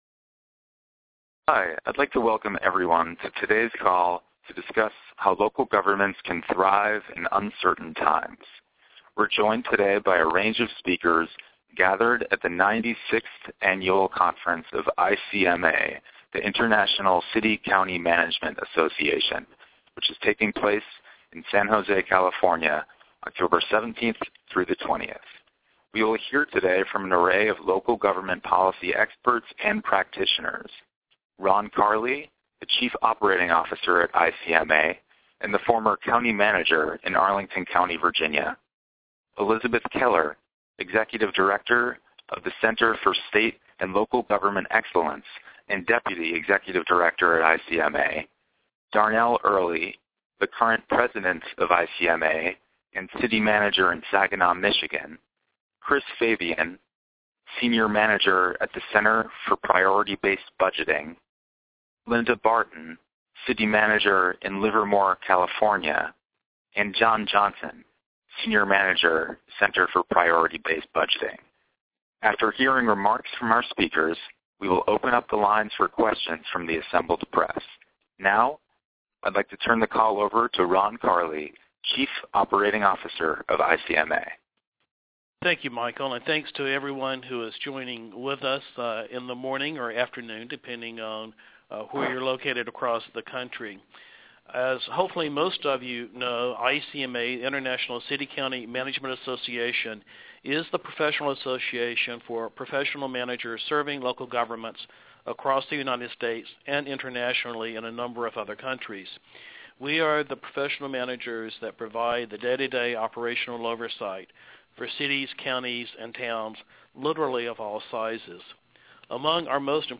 A teleconference broadcast from ICMA's 96th Annual Conference in San Jose, California, on thriving in uncertain times.
Assembled members of the press asked questions after the participant comments.